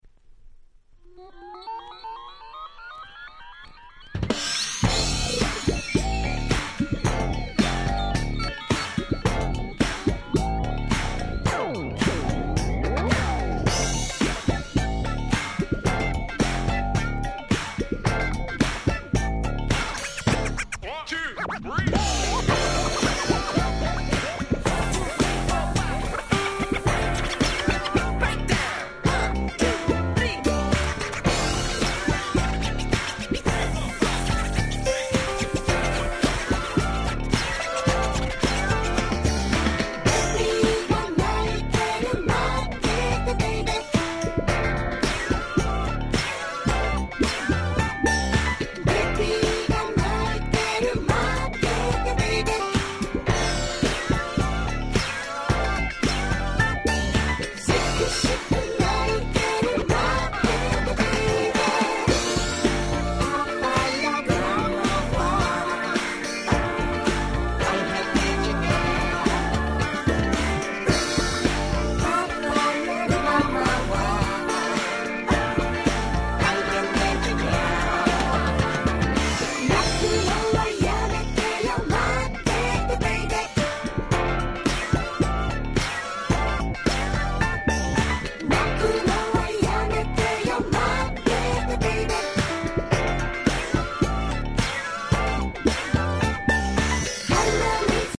Dance Classicsの往年の名曲達を面白楽しく日本語で替え歌してしまった非常にユーモア溢れるシリーズ！(笑)
Disco ディスコ